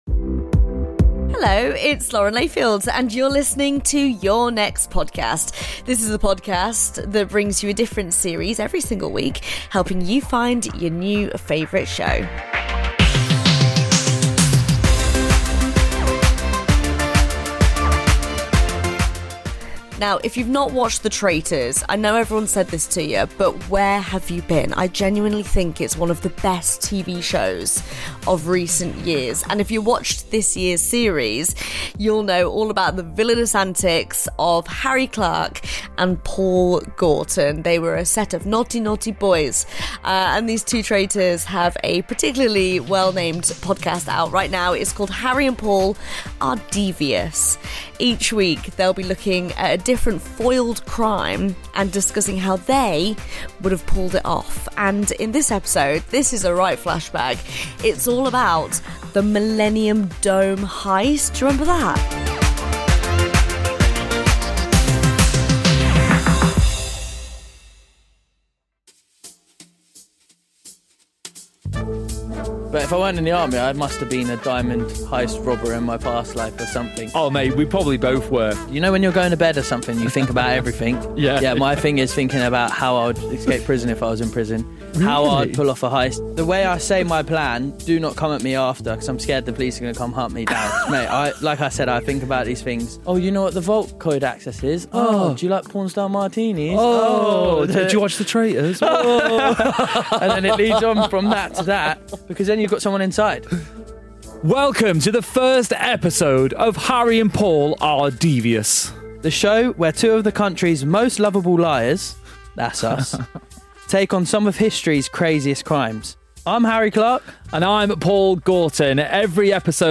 Lauren Layfield introduces Harry and Paul Are... Devious on the series recommendation show, Your Next Podcast.